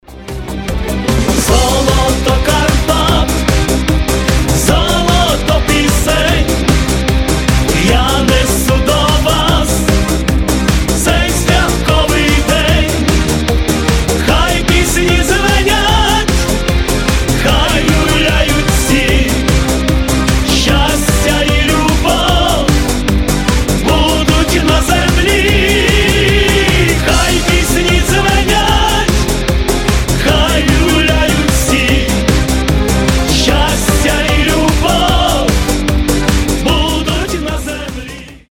• Качество: 320, Stereo
добрые
патриотические
эстрадные